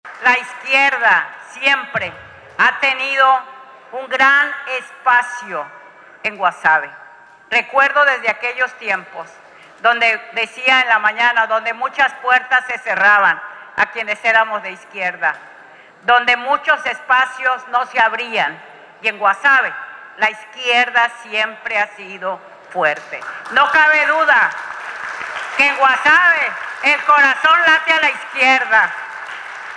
Guasave, Sinaloa 18 de octubre de 2025.- Atendiendo la invitación de los representantes del Partido Verde Ecologista de México, el gobernador Rubén Rocha Moya, acudió, al primer informe de labores de la y el diputado del grupo parlamentario, por los distritos 07 y 08, quienes, en un acto de rendición de cuentas ante la sociedad compartieron los resultados de su trabajo del último año en el Congreso del Estado.